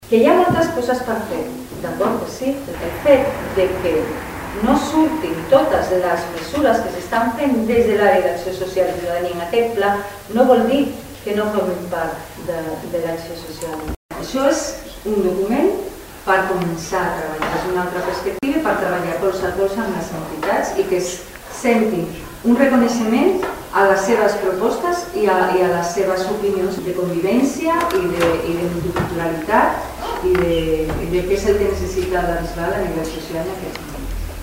Maite Bravo defensava d’aquesta manera la bondat del seu pla social